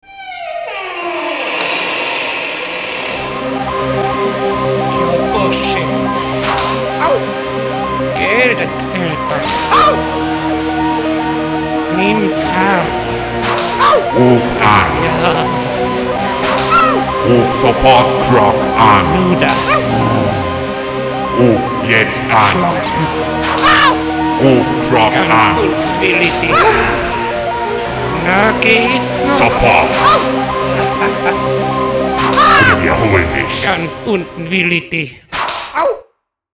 Rockband für Partys und Veranstaltungen buchen